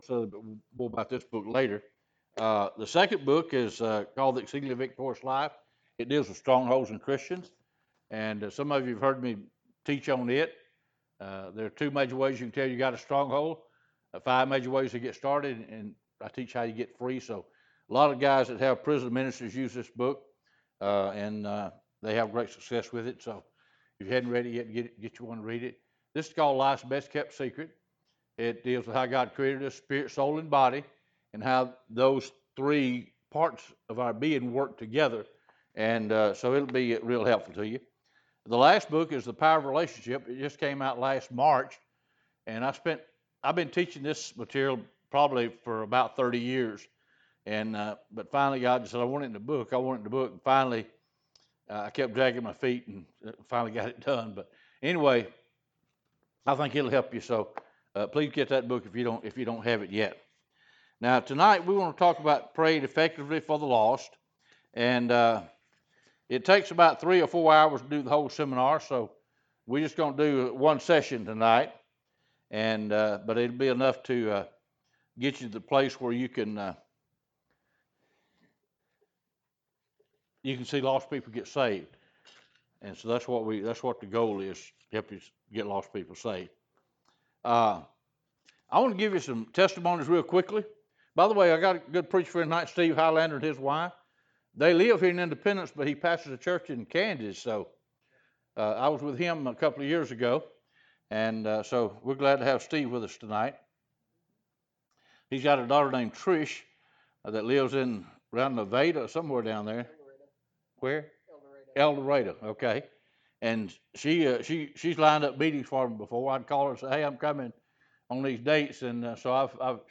Enjoy this teaching, it will thoroughly bless you and give you powerful weapons to pray for the lost.